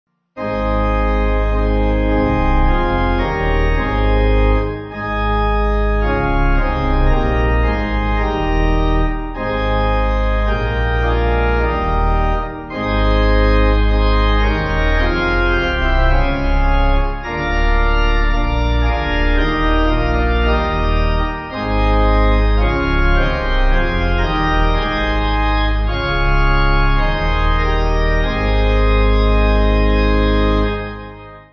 (CM)   4/Ab